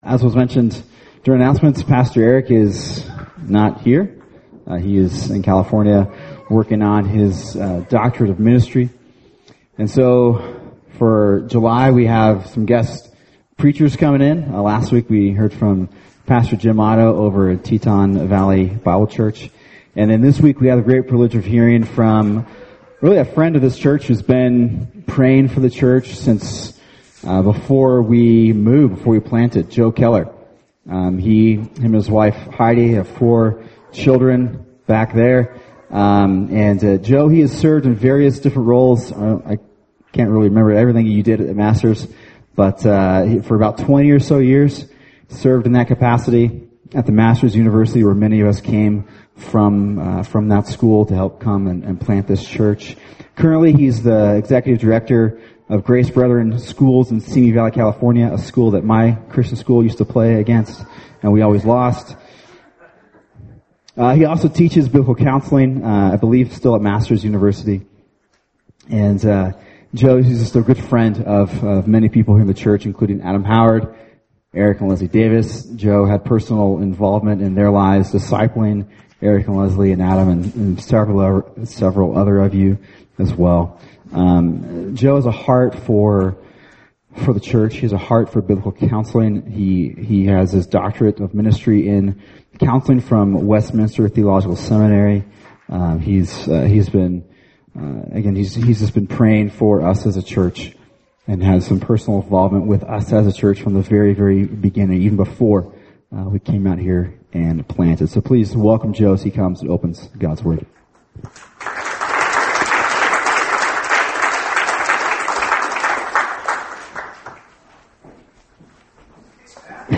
[sermon] Colossians 3:9-17 – Gospel Transformed Relationships | Cornerstone Church - Jackson Hole